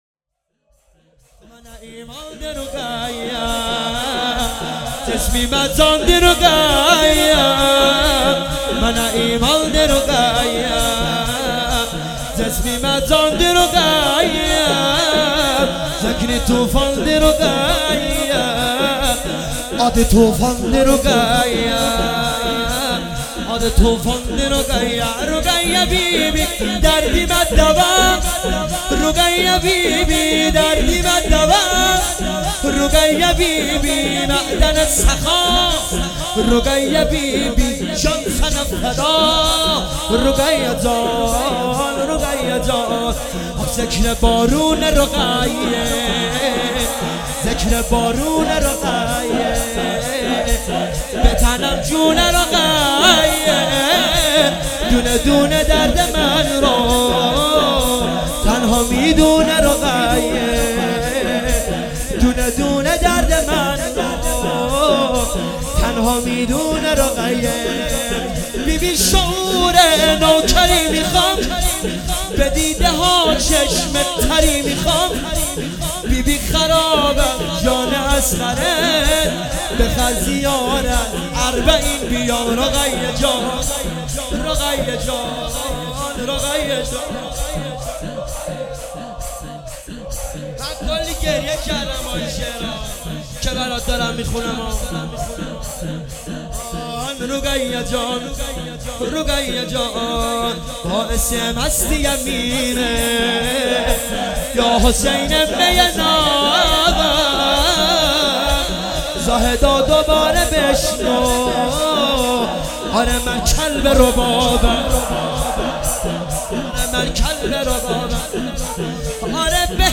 مداحی
نوحه
شور